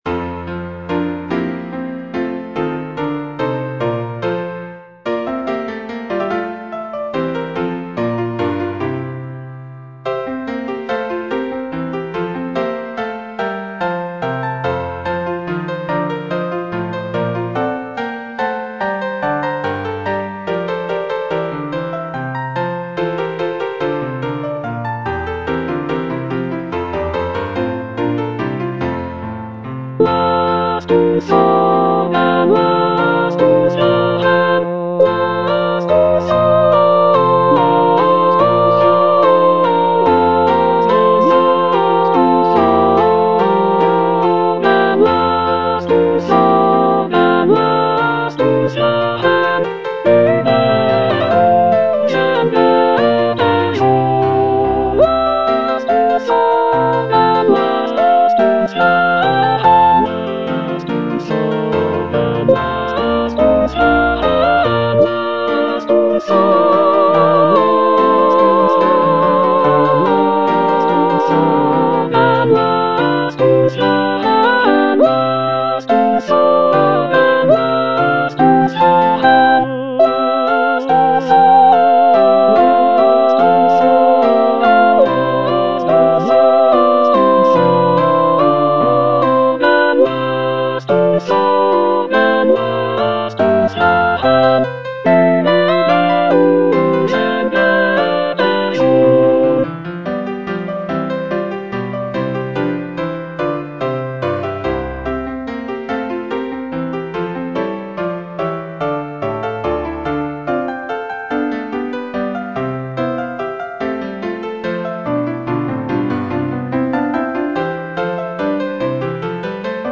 Choralplayer playing Cantata
The cantata features a celebratory and joyful tone, with arias and recitatives praising the prince and his virtues. It is scored for soloists, choir, and orchestra, and showcases Bach's mastery of counterpoint and vocal writing.